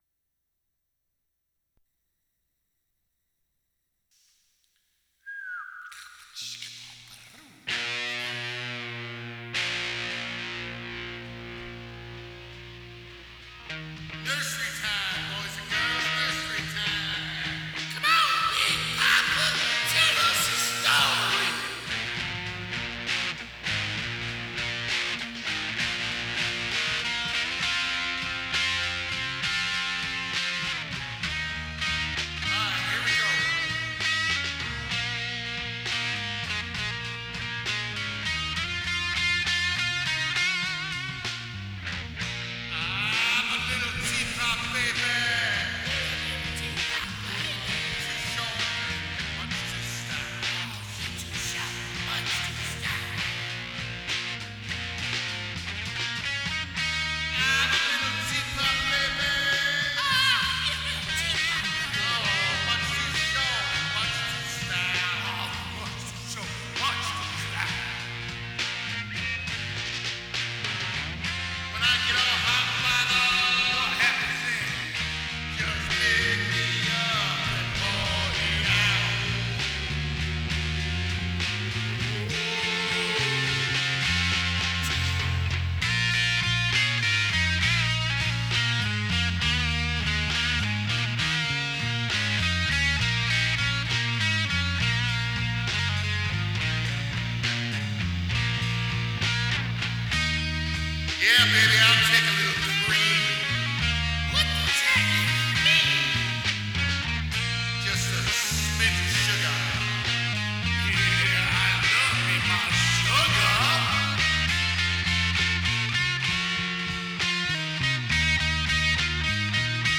drums
bass, recorded live 1/2016